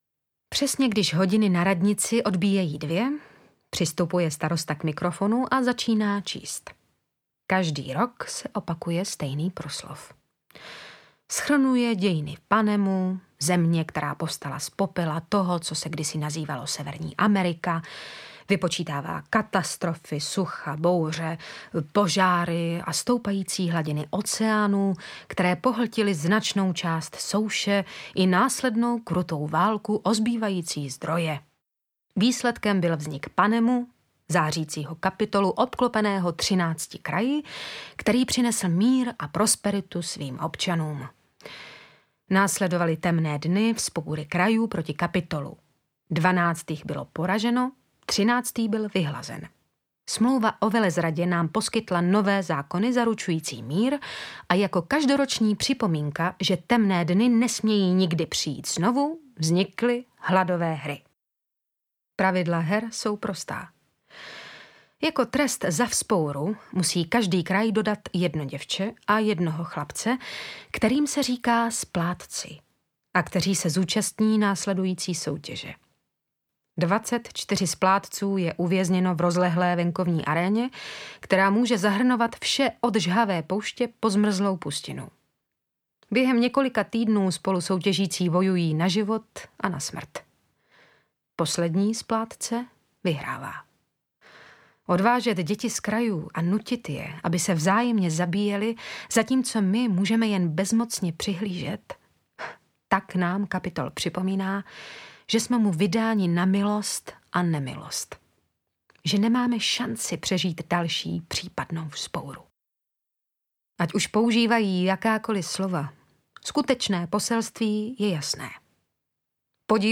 Hunger Games 1 - Aréna smrti audiokniha
Ukázka z knihy